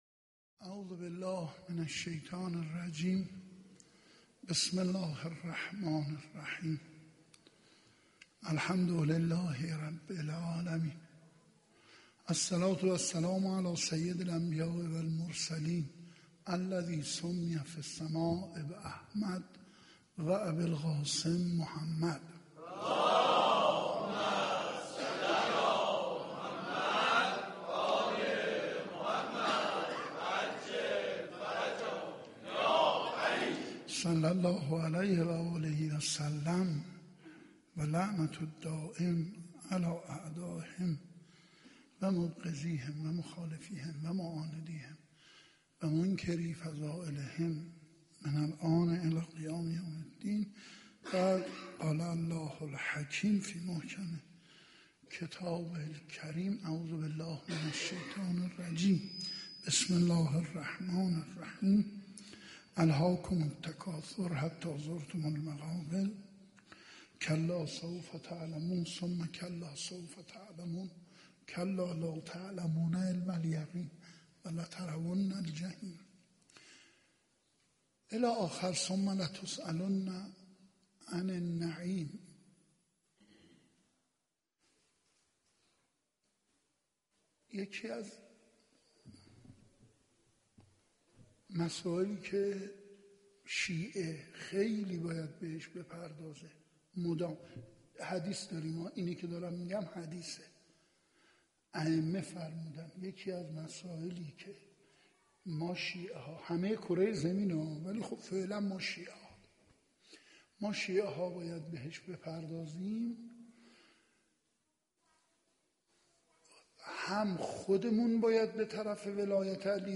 خیمه گاه - حسینیه کربلا - لیله الرغائب - 27 اردیبهشت 1392
سخنرانی